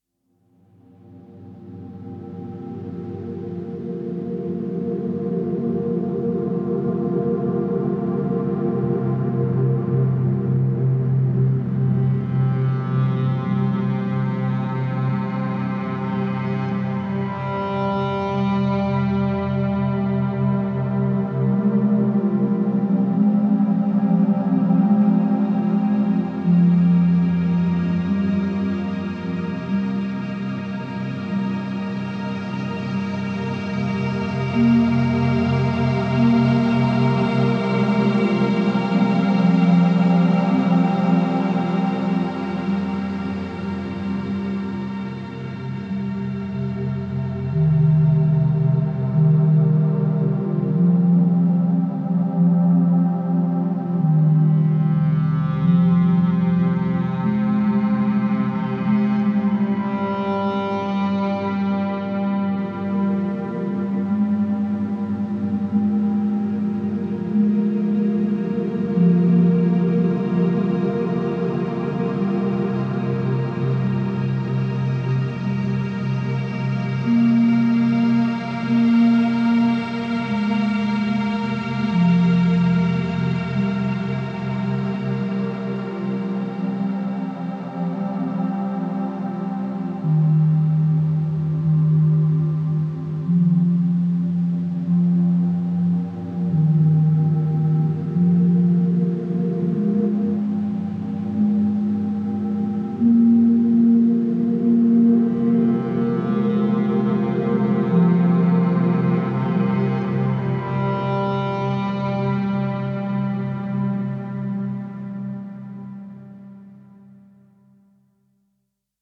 Melancholic cello and strings float gently in the night.